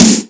edm-snare-39.wav